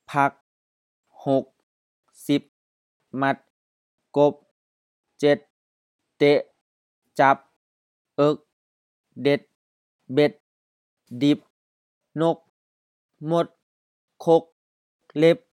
This website is concerned with the variety spoken in Khon Kaen province.
Recordings of the Gedney word list (A1234, B1234, C1234, DL1234, DS1234)